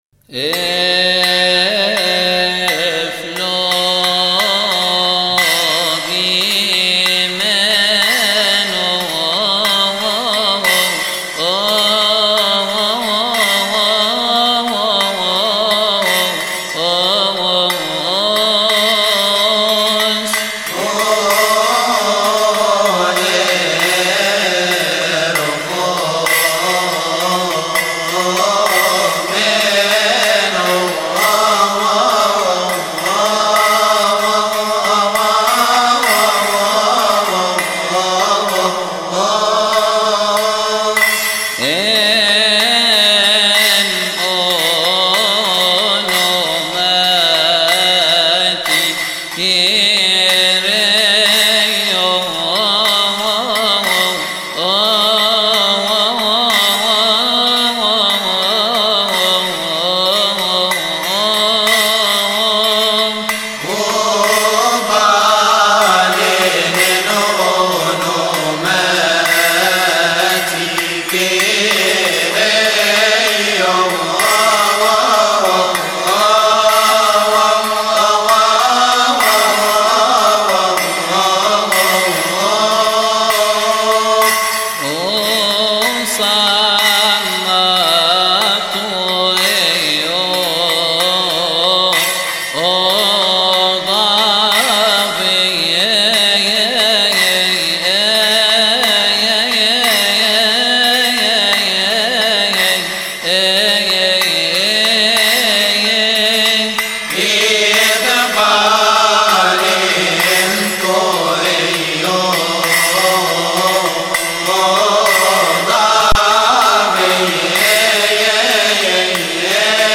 لحن افلوجيمينوس.mp3